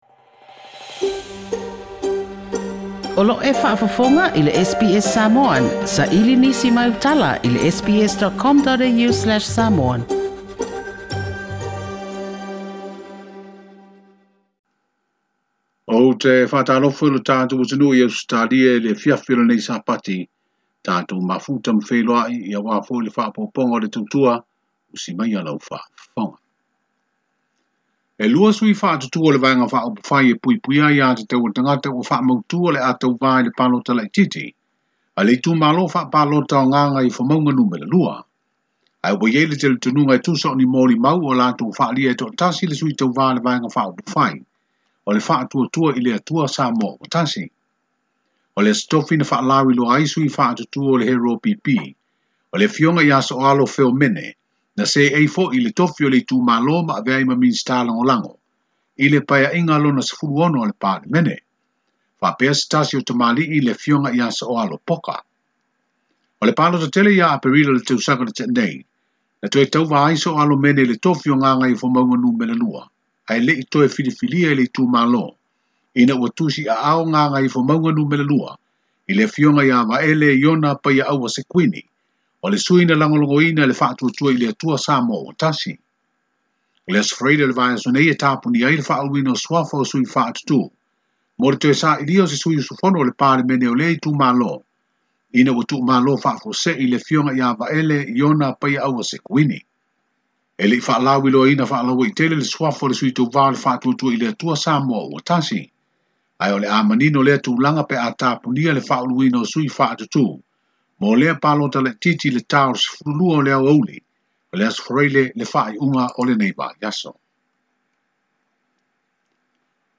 Faafofoga i le ripoti o talafou mai Apia